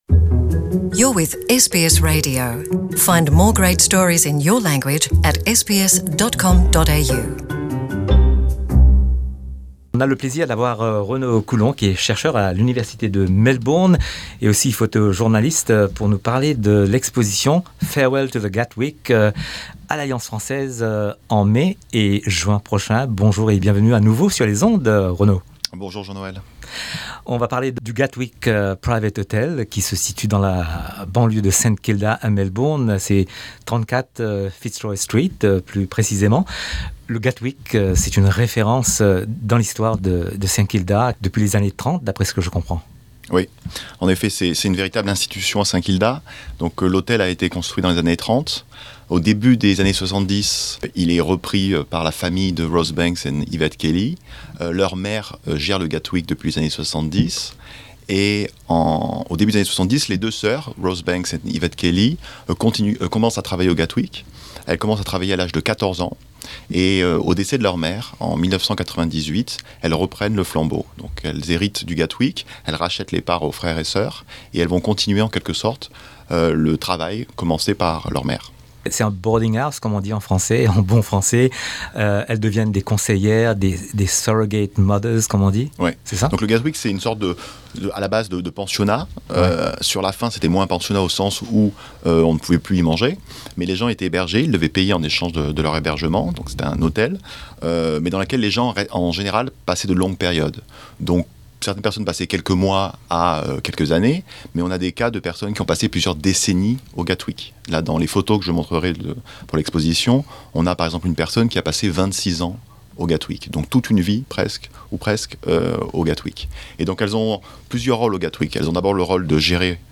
Cette interview a été publiée en novembre 2018.